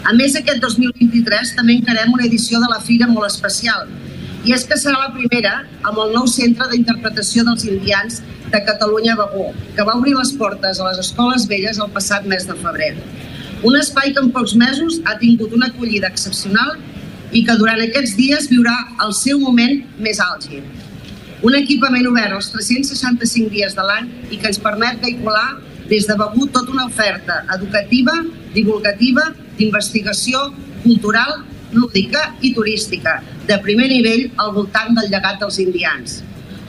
Maite Selva, alcaldessa de Begur, explica que “la Fira d’Indians ha crescut i evolucionat molt des de la seva arrencada.